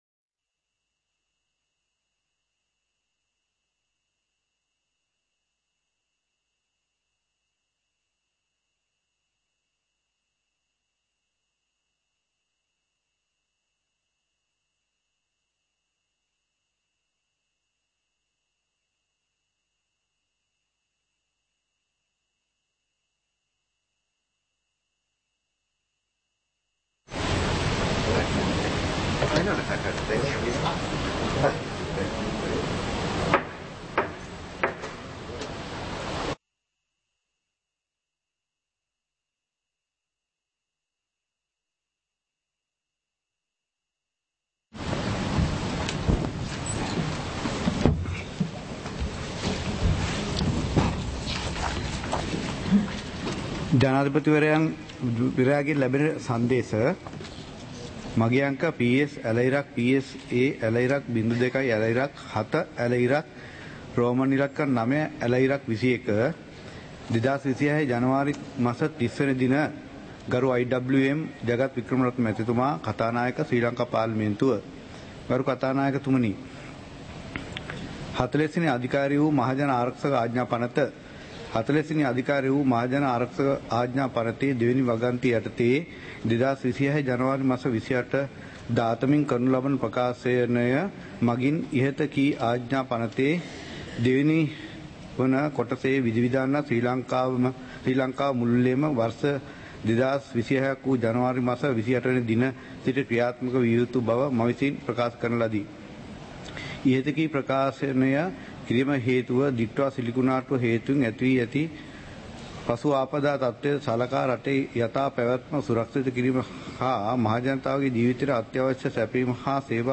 Proceedings of the House (2026-02-03)
Parliament Live - Recorded